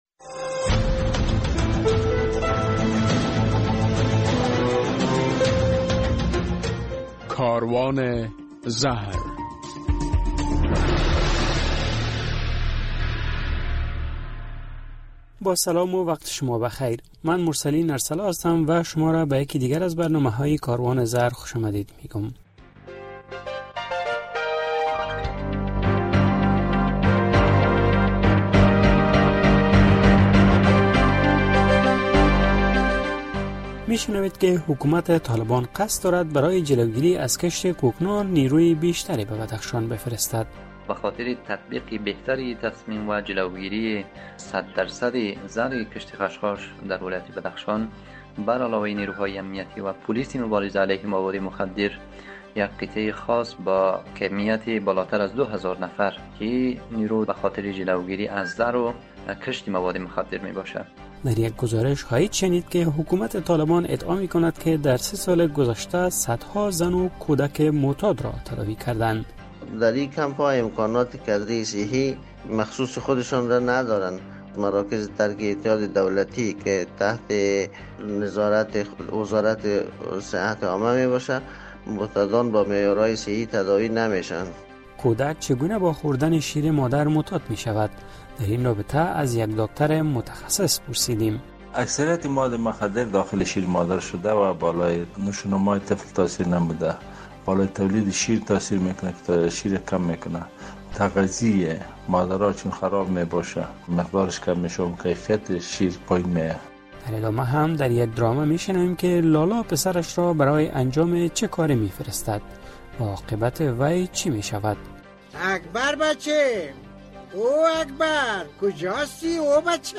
در این برنامه کاروان زهر می‌شنوید که حکومت طالبان برای جلوگیری از کشت کوکنار دو هزار نظامی دیگر به ولایت بدخشان می فرستد. در یک گزارش خواهید شنید که حکومت طالبان ادعا می‌کند که در سه سال بیش از چهار هزار زن و کودک معتاد به مواد مخدر را تداوی کرده اند. در مصاحبه با یک داکتر از وی پرسیدیم که...